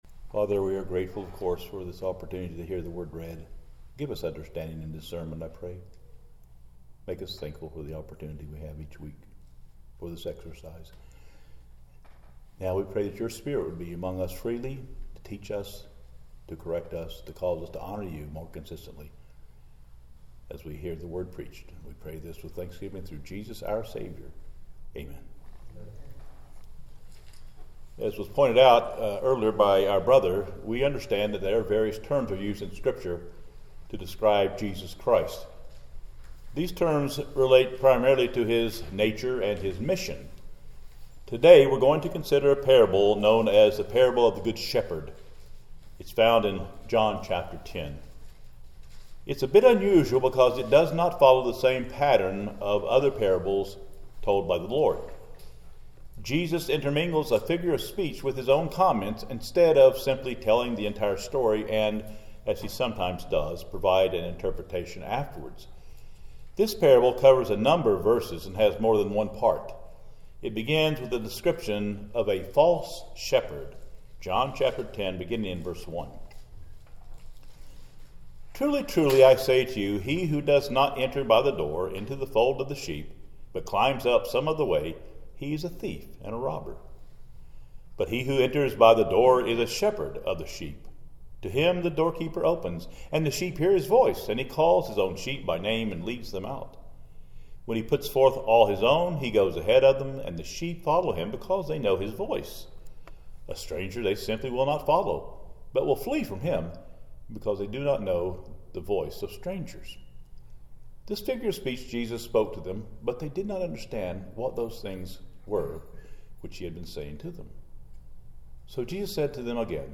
John 10:1–18 Service Type: Sunday Service Topics